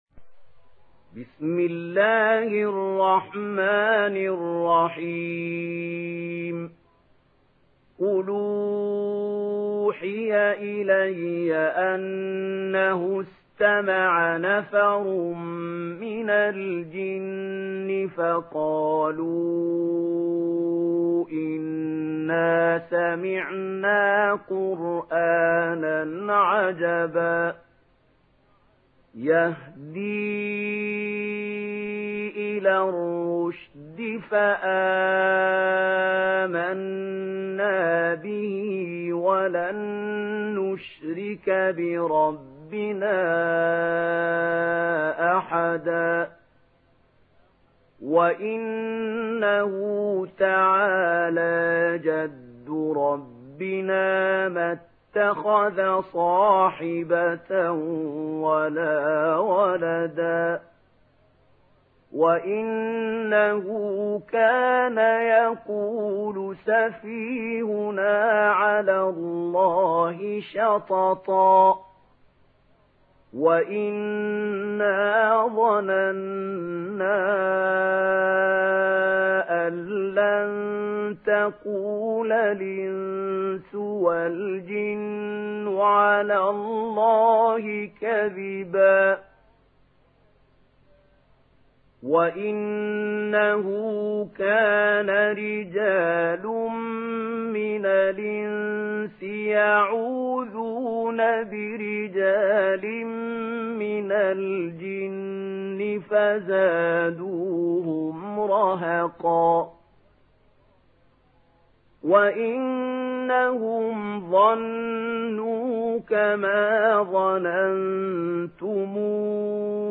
Surah Al Jinn Download mp3 Mahmoud Khalil Al Hussary Riwayat Warsh from Nafi, Download Quran and listen mp3 full direct links